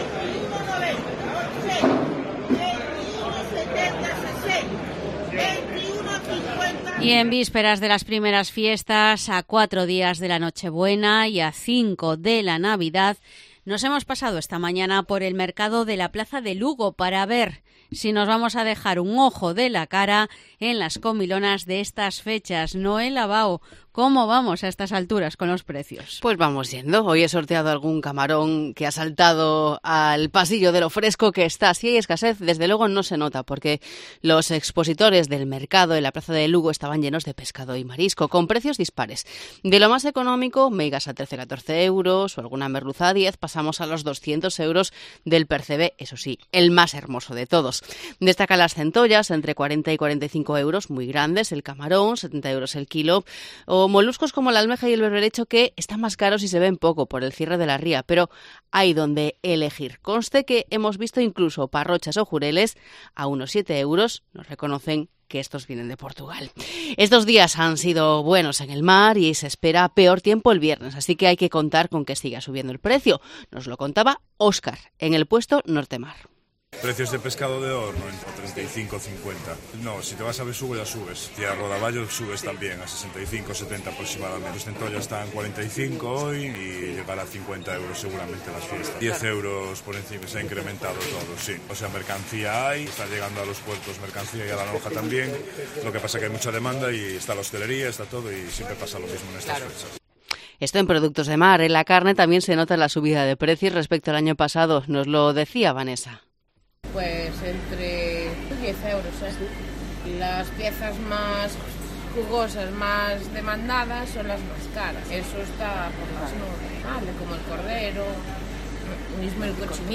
Crónica desde la plaza de Lugo